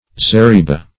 Zeriba \Ze*ri"ba\, n. (Mil.)